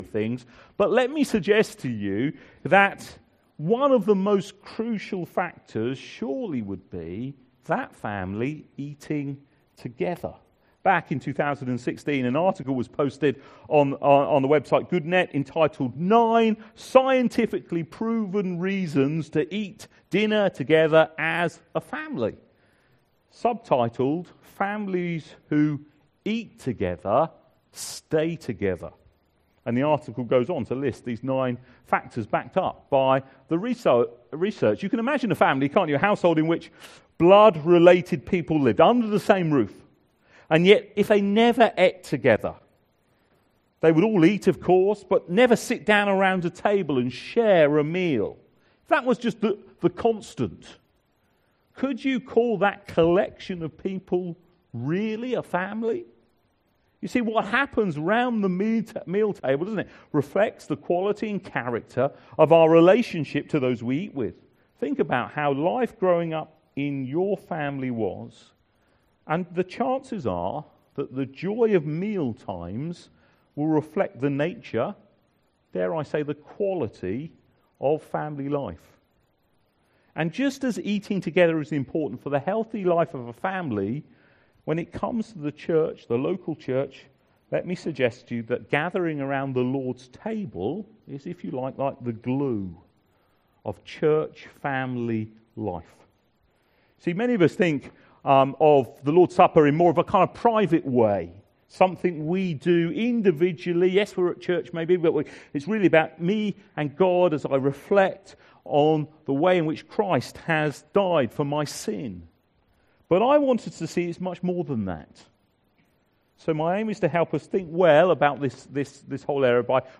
LABC Sermons